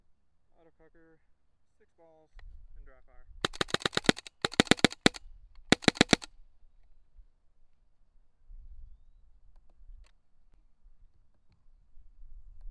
autococker_raw_notclipped_02.wav